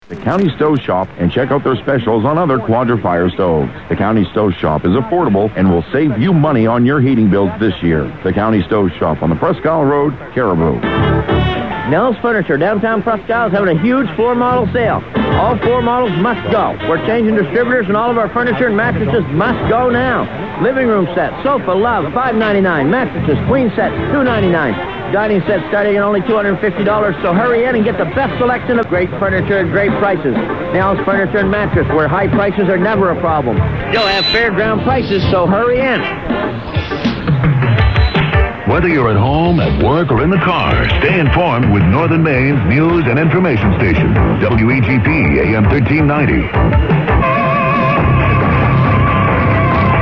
Great audio on that little set (406k)